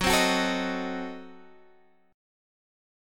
Gbdim7 chord